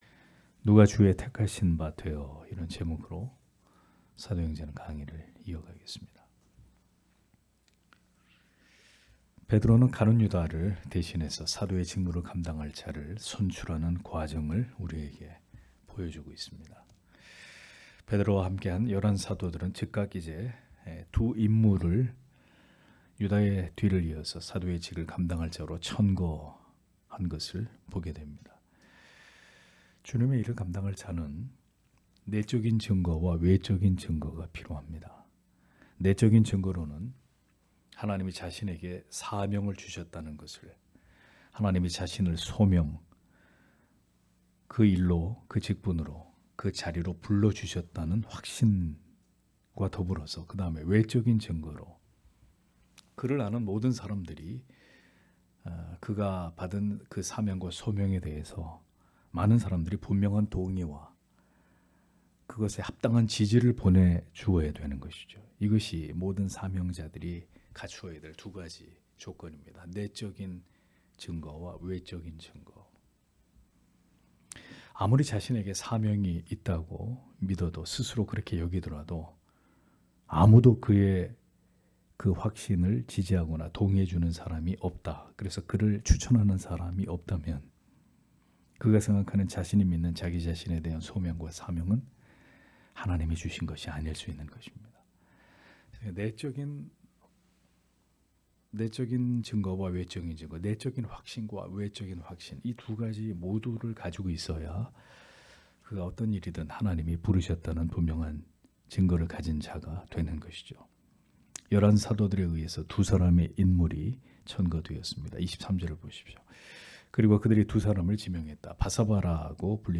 금요기도회 - [사도행전 강해 08] 누가 주의 택하신바 되어 (행 1장 23- 26절)